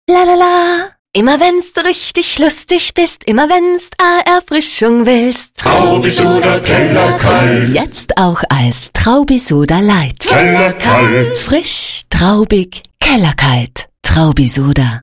Der neue Traubisoda Radiospot ist jetzt on air.